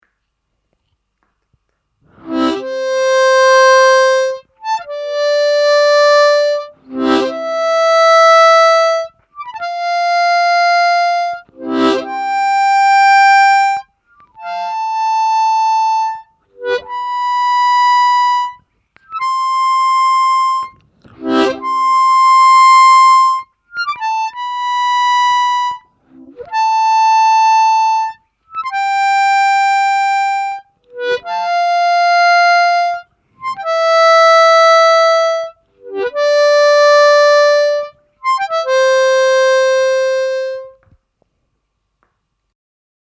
Слайд (глиссандо) и дроп-офф на губной гармошке
Сыграть гамму до-мажор +4-4+5-5+6-6-7+7 и обратно +7-7-6+6-5+5-4+4, к каждой ноте подъезжая техникой слайд (чередуя слайд снизу со слайдом сверху).